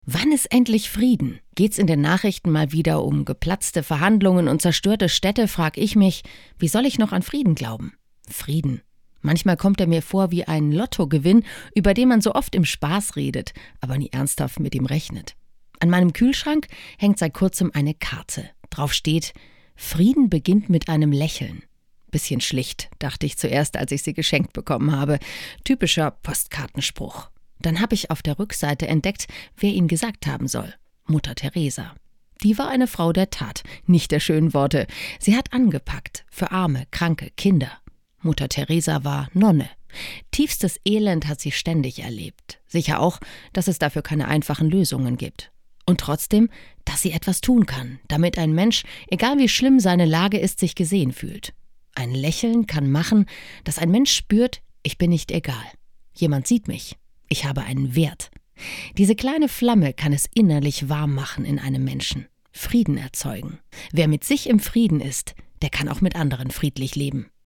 Startseite > andacht > Nur ein Lächeln